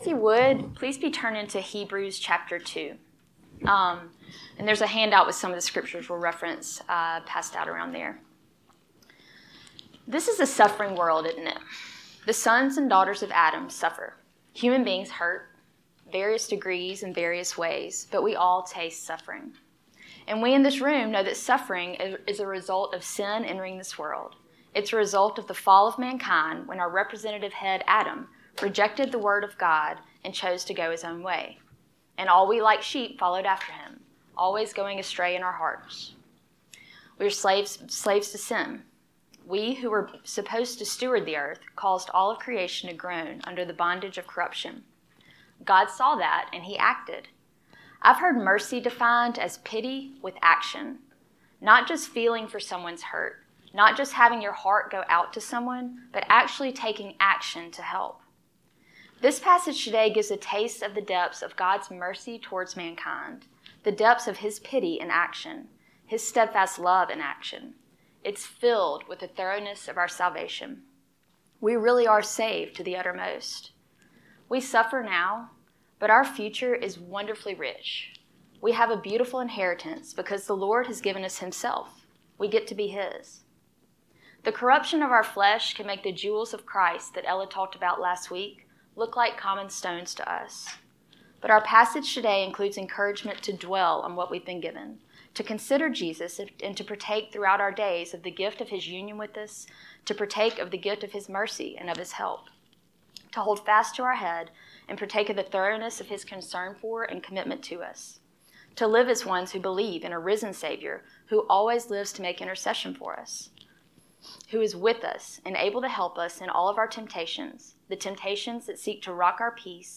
Hebrews Lesson 4